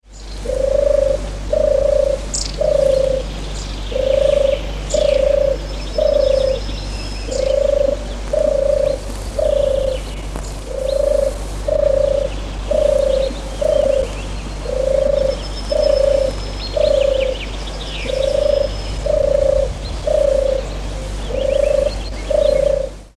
Streptopelia_turtur_M0007_24_ - NAJUversum - die neue Internet-Plattform für Kinder, Eltern und Lehrer.
Genre: Animal Sound Recording.